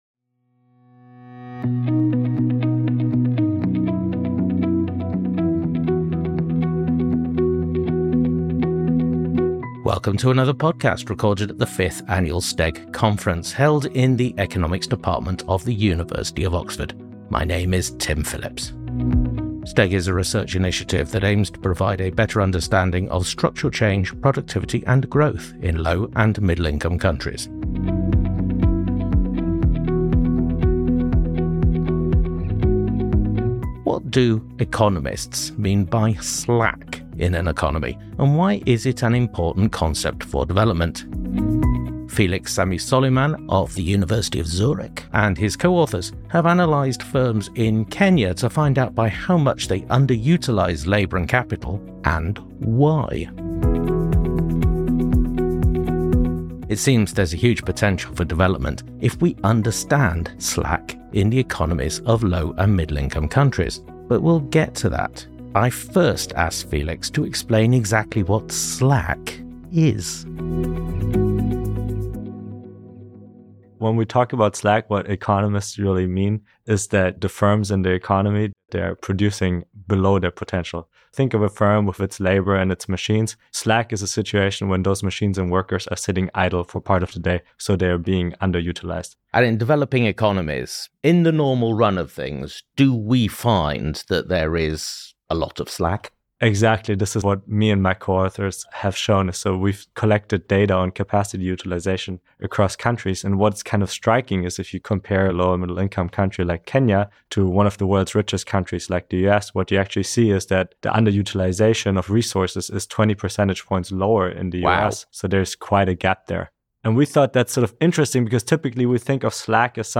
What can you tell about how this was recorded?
Recorded at the 2025 annual conference of the Structural Transformation and Economic Growth Programme, held at the University of Oxford.